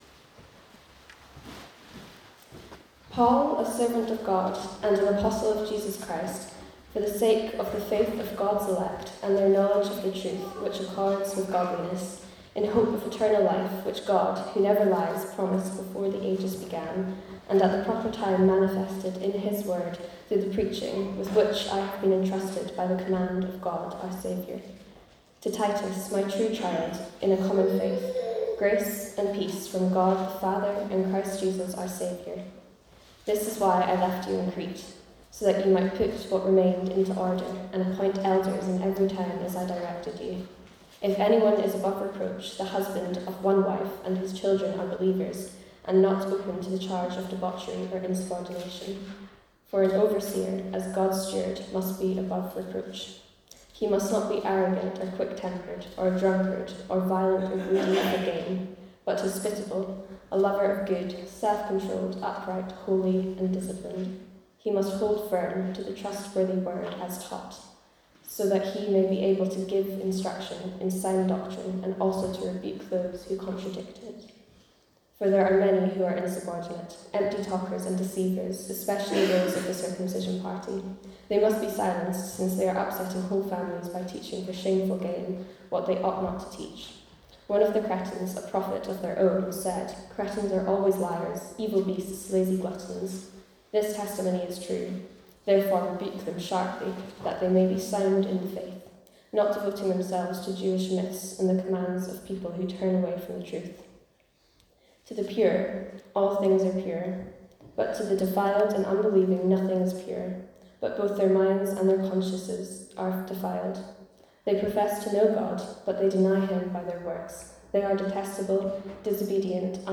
Sermons | St Andrews Free Church
Women's Teaching Day 2026